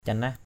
/ca-nah/ 1.